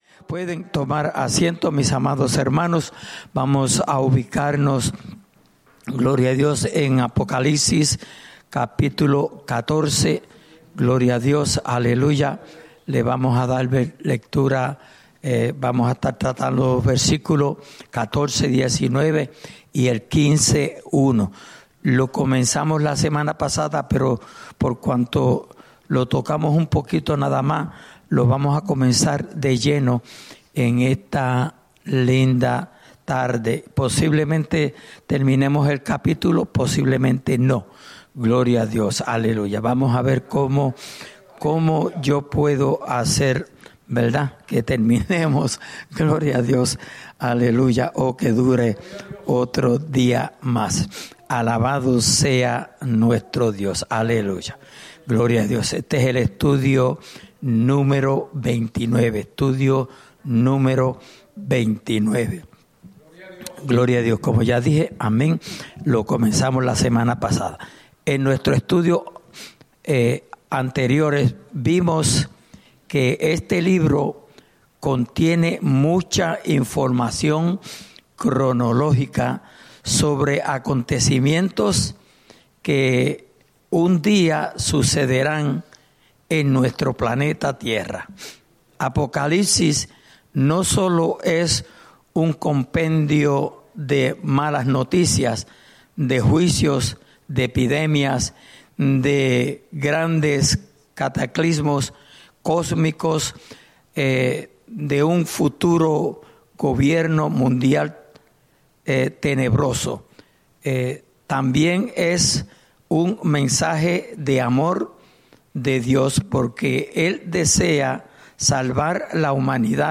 Estudio Bíblico: Libro de Apocalipsis (Parte 29)